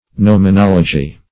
Search Result for " gnomonology" : The Collaborative International Dictionary of English v.0.48: Gnomonology \Gno`mon*ol"o*gy\, n. [Gnomon + -logy.